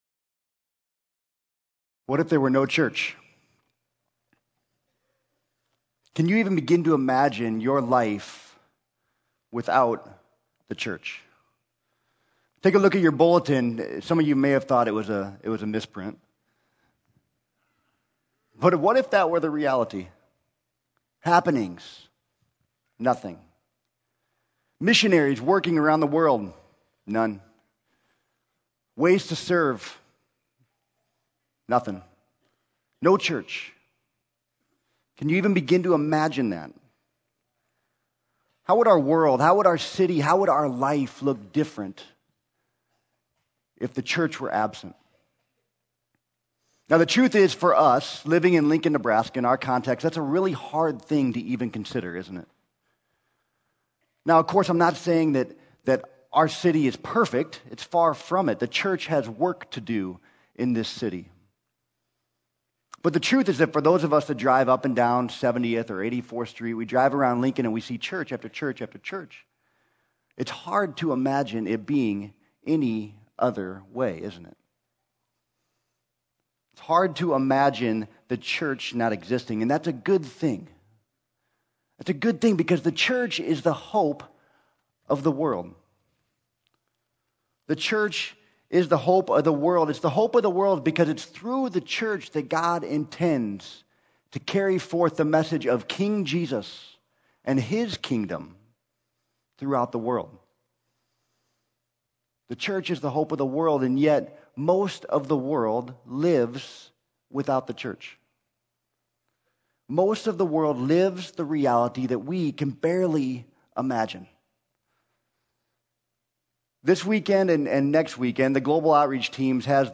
Sermon: What If There Were No Church